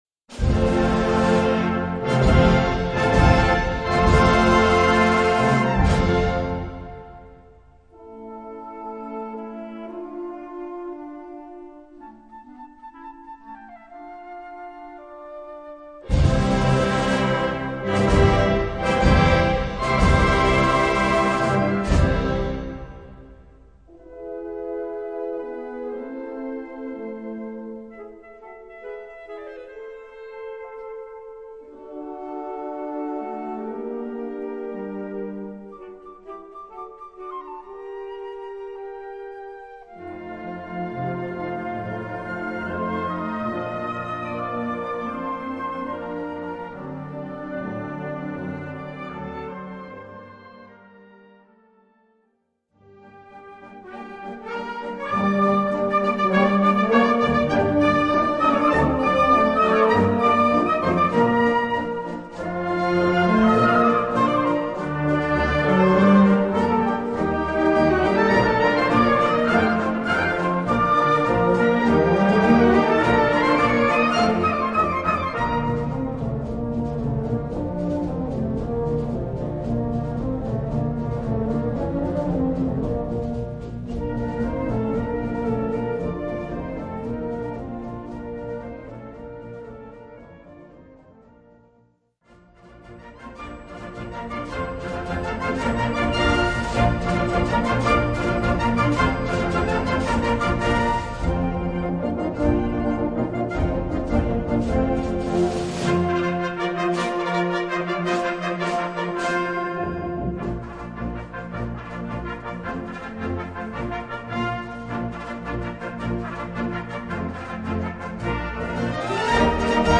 Gattung: Ouverture à la Rossini
Besetzung: Blasorchester